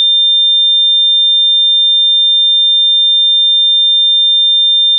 LUZ FIJA DE LED, SONIDO CONTINUO
Sonido continuo.
Sonido:85dB (3600Hz)